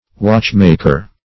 \Watch"mak`er\